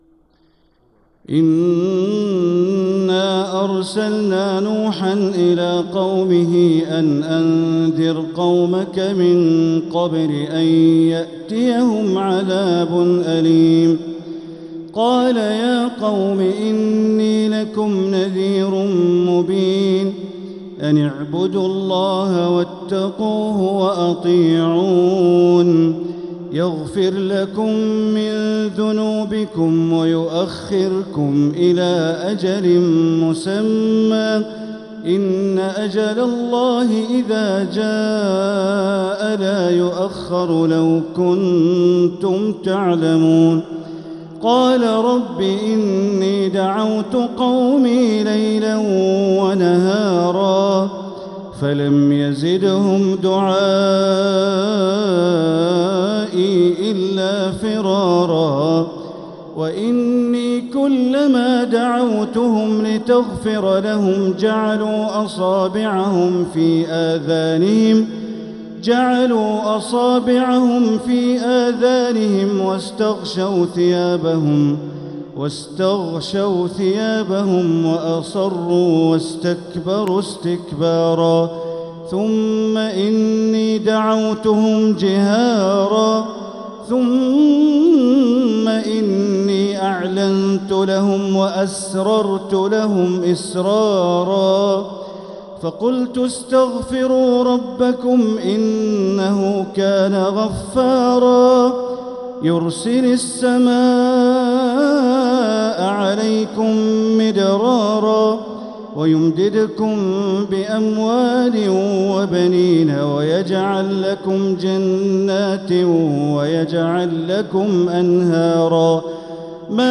سورة نوح | مصحف تراويح الحرم المكي عام 1446هـ > مصحف تراويح الحرم المكي عام 1446هـ > المصحف - تلاوات الحرمين